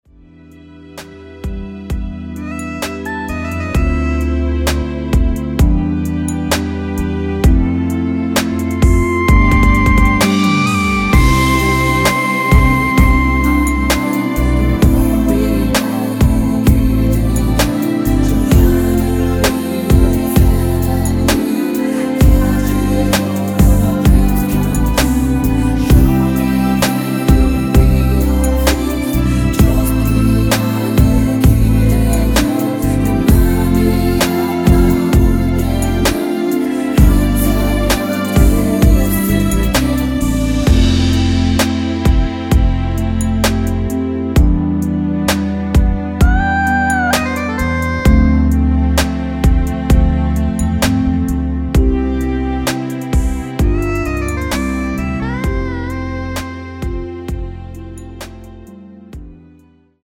원키에서(+1)올린 코러스 포함된 MR 입니다.
◈ 곡명 옆 (-1)은 반음 내림, (+1)은 반음 올림 입니다.
앞부분30초, 뒷부분30초씩 편집해서 올려 드리고 있습니다.
중간에 음이 끈어지고 다시 나오는 이유는